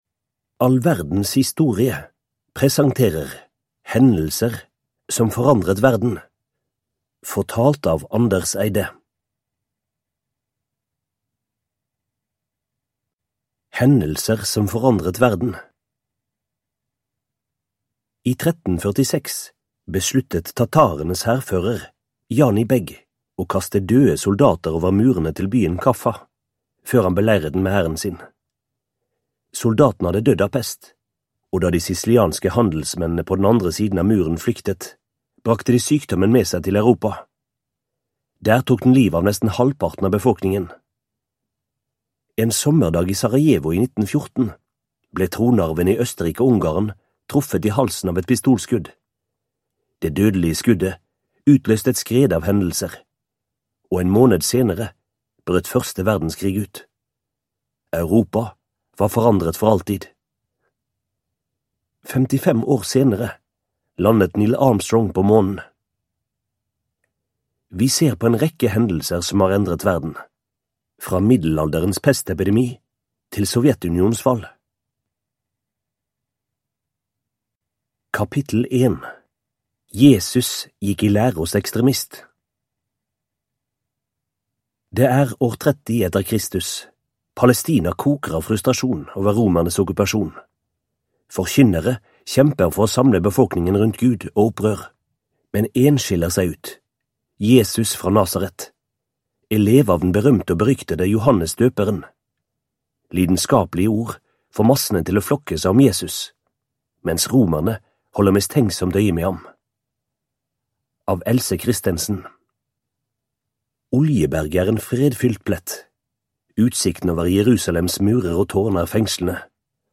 Hendelser som forandret verden (ljudbok) av All Verdens Historie